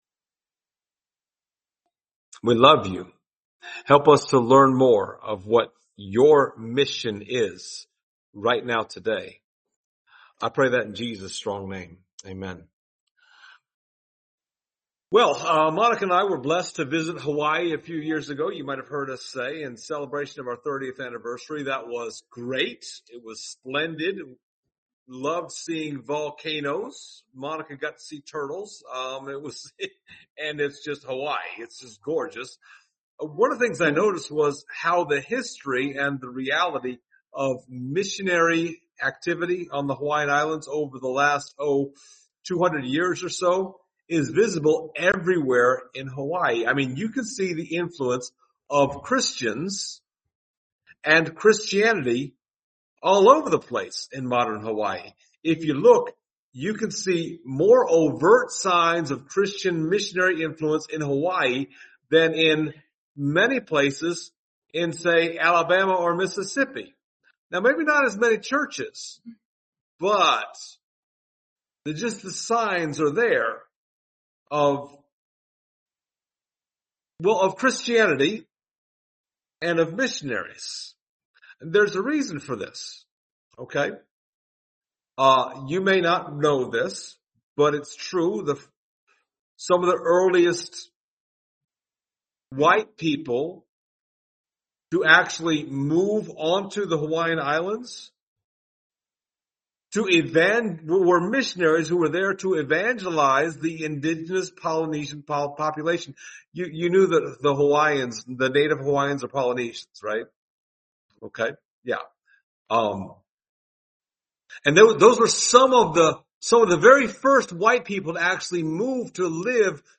Acts 16:9-10 Service Type: Sunday Morning Topics